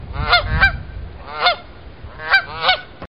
Ses Efektleri